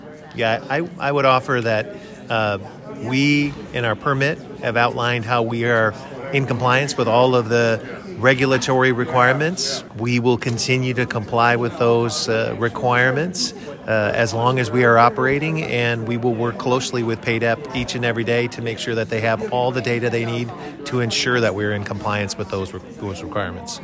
Wednesday night, the Pennsylvania Department of Environmental Protection and Homer City Redevelopment held a public hearing to address the public’s concerns about the proposed air quality treatment plan for the proposed natural gas-fired power plant in Center Township.